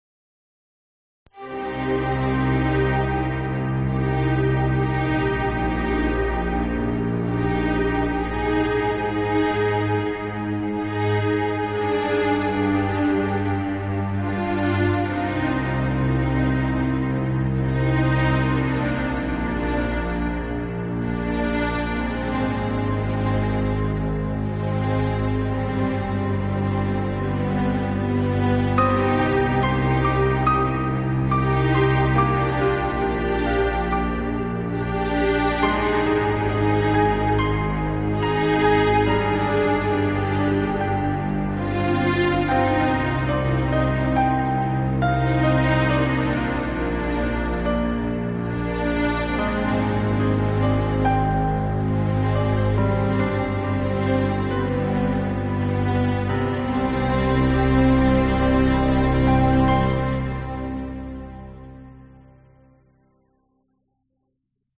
描述：管弦乐悲情主题（弦乐+钢琴）
Tag: 管弦乐 钢琴 悲伤 小提琴